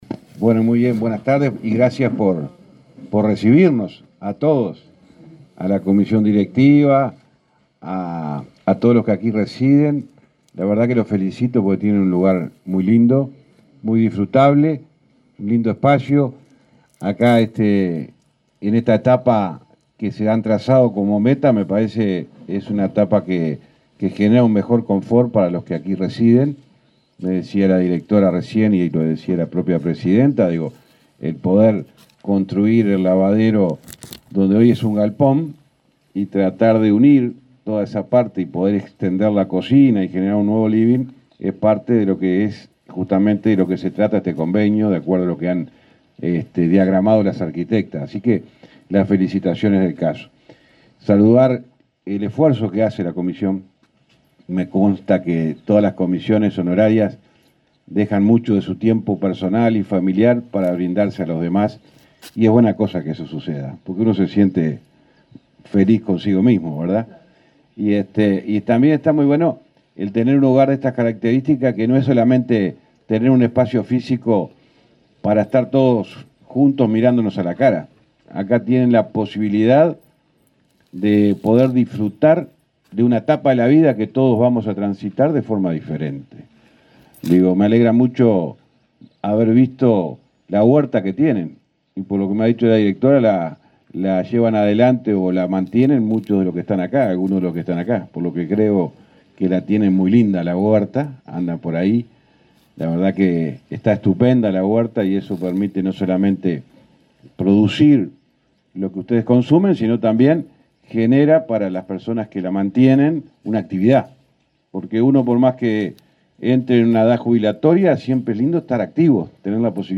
Palabras del ministro de Transporte, José Luis Falero